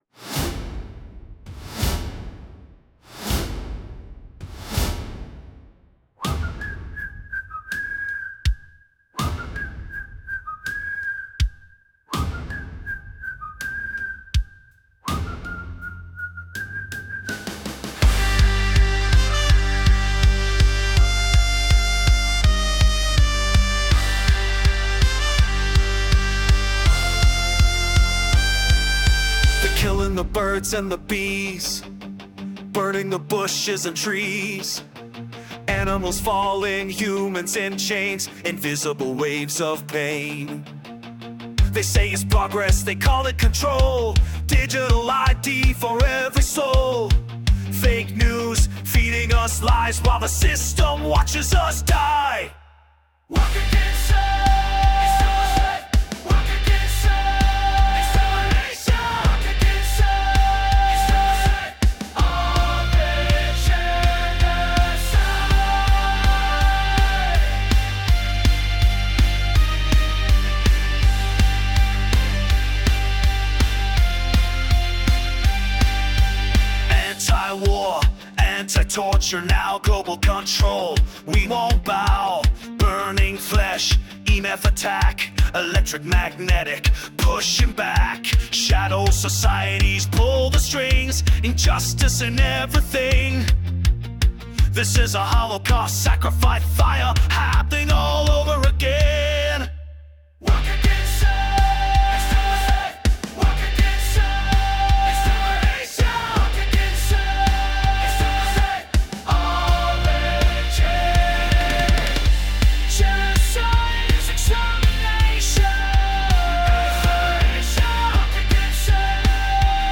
faster, chant heavy